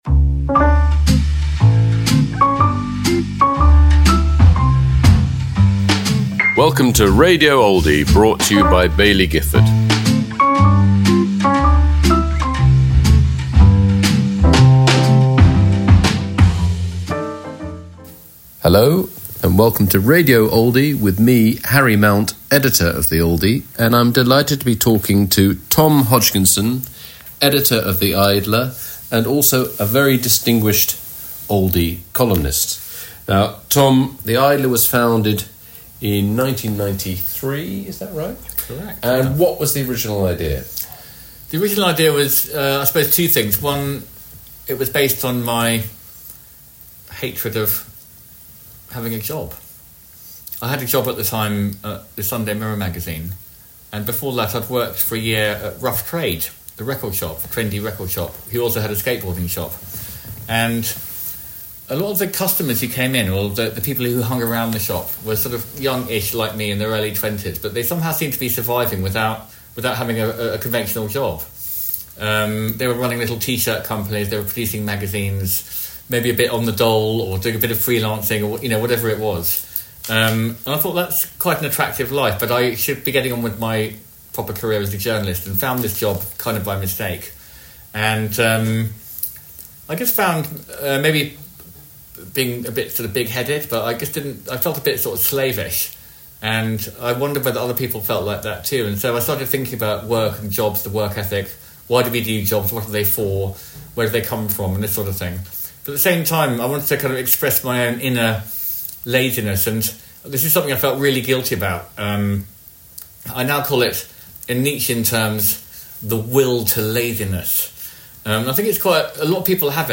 The Oldie magazine’s podcast featuring discussion and debate around the lead features in the latest magazine, plus live recordings from our famous Literary Lunches.